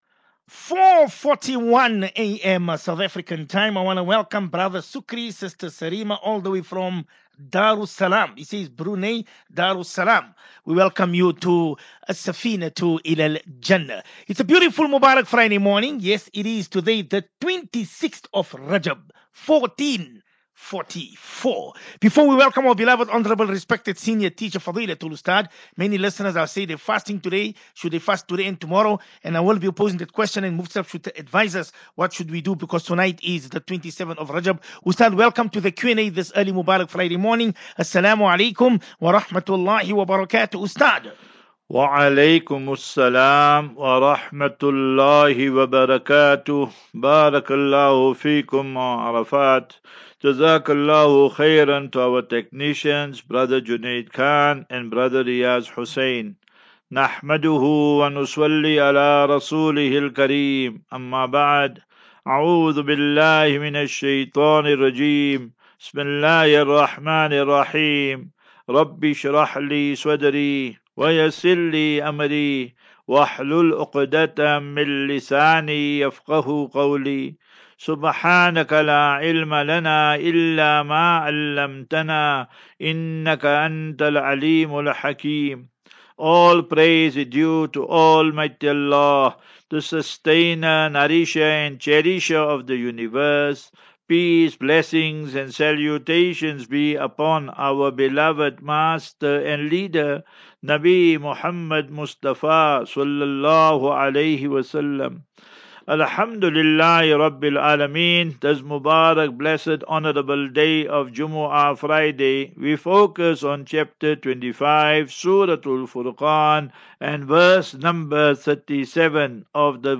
View Promo Continue Install As Safinatu Ilal Jannah Naseeha and Q and A 17 Feb 17 Feb 23- Assafinatu-Illal Jannah 37 MIN Download